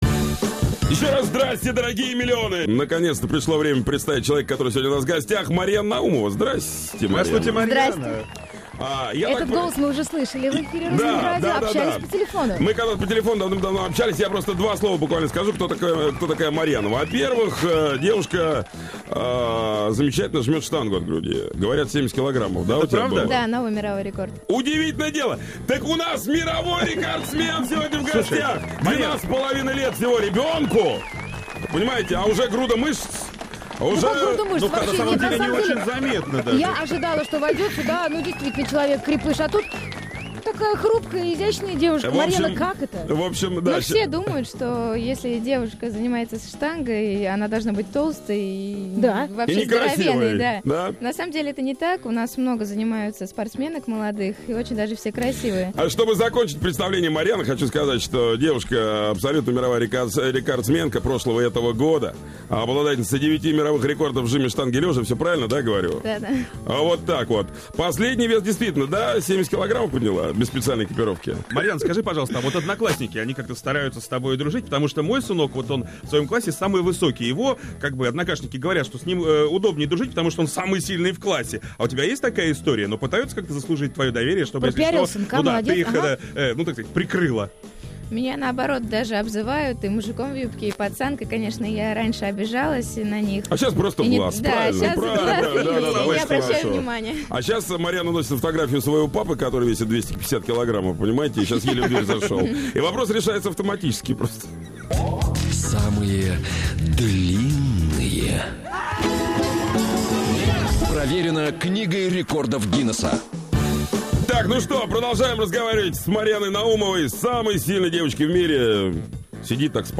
Утреннее шоу "Русские Перцы" на "Русском Радио": Рекордсмены Гиннесса "пожали руку" новой мировой рекордсменке, сошлись в поединке по армрестлингу.